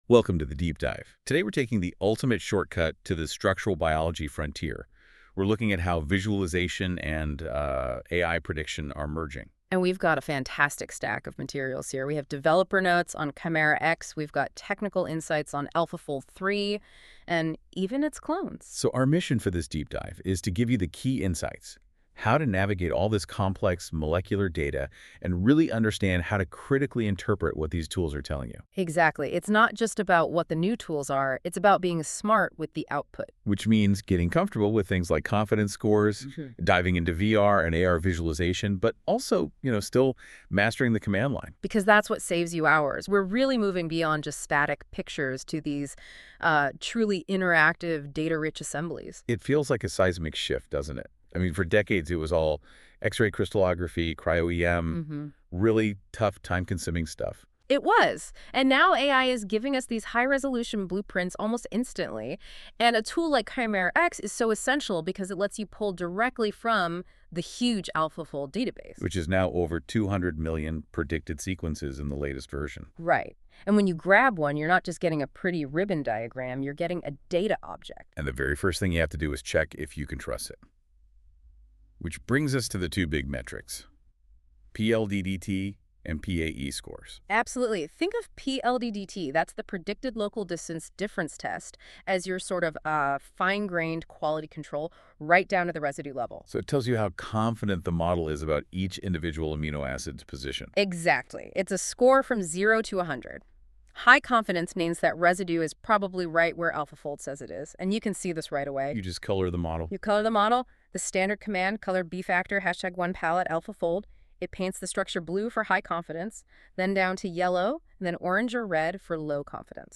NotebookLM can make podcasts (audio), reports on specific topics, "mind maps", videos and flashcards covering specific topics. I had it make a summary podcast without specifying a topic (12 minutes) which was frigheningly slick, and included some wrong information.